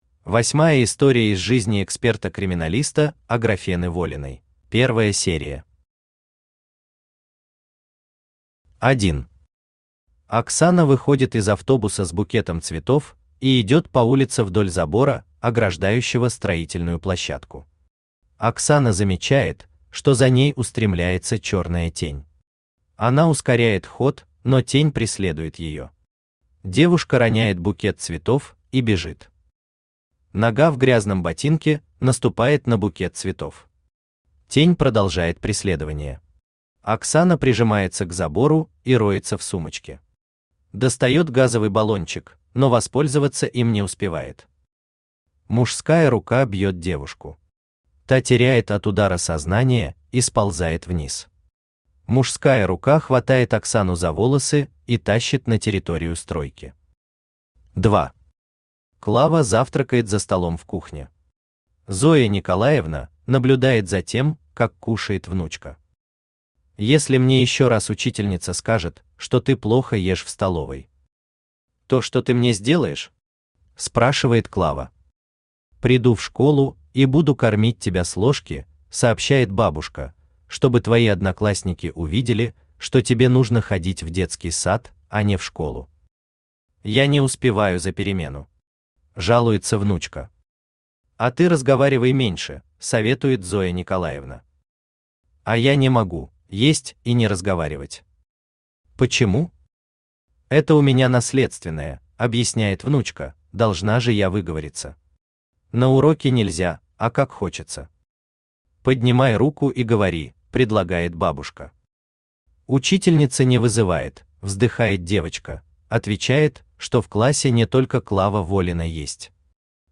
Аудиокнига Чупакабра. Деменция | Библиотека аудиокниг
Деменция Автор Сергей Алексеевич Глазков Читает аудиокнигу Авточтец ЛитРес.